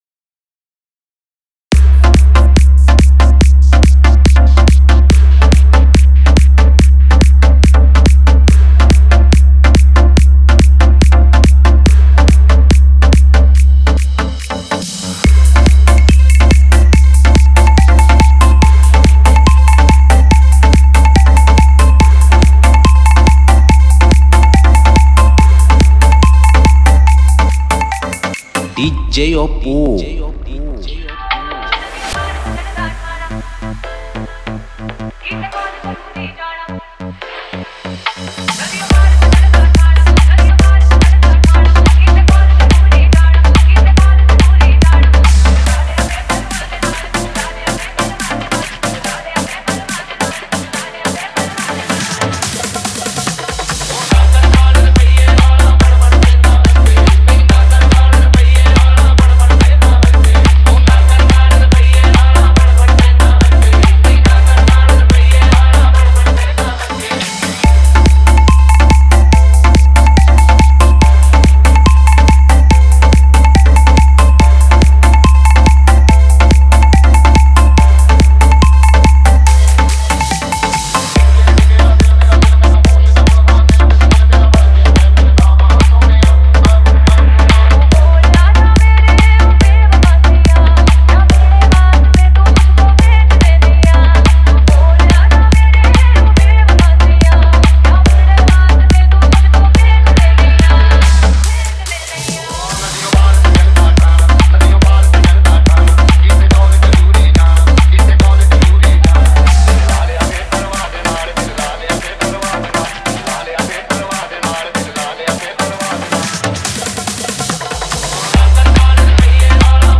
New Bollywood EDM Dance Remix 2021